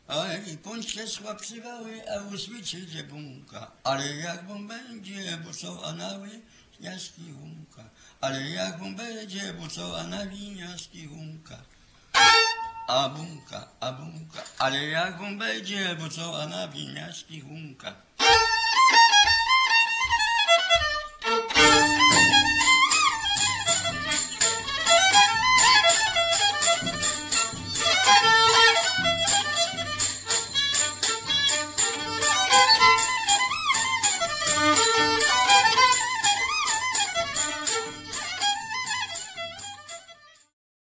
Mazurek
Badania terenowe
skrzypce
bębenek